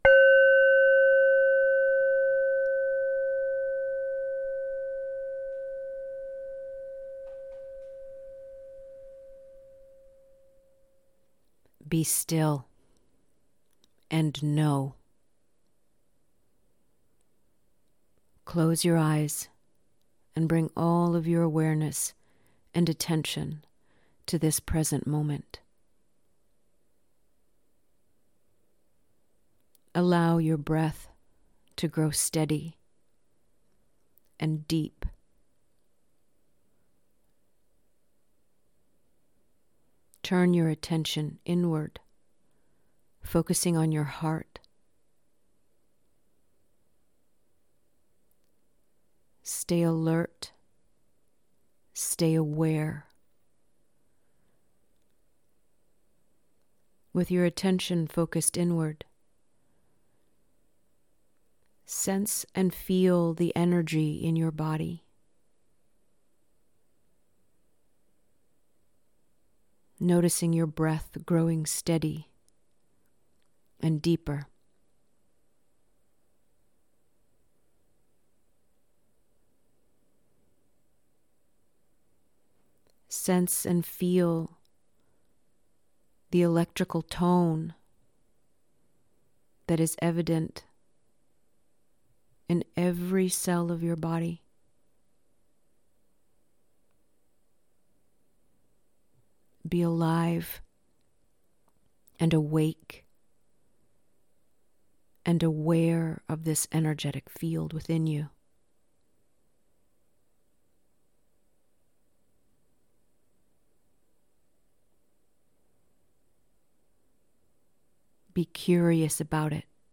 BE-STILL-Guided-Meditation.mp3